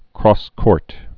(krôskôrt, krŏs-)